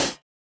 Hat (SHE).wav